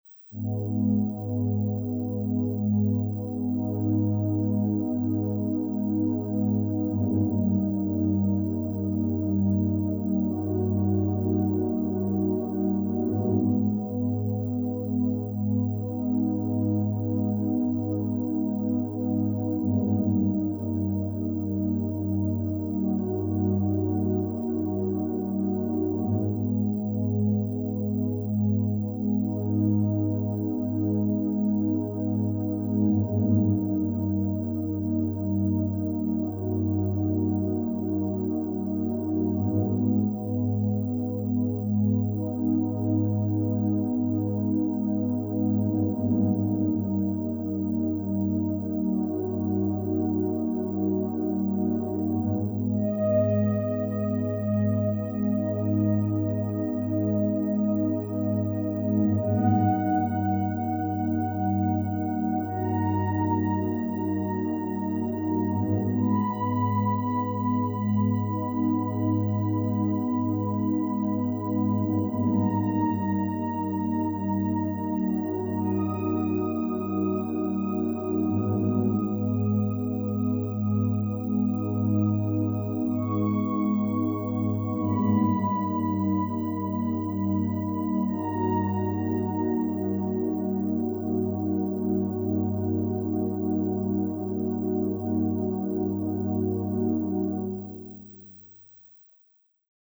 Orchestral & Instrumental Composer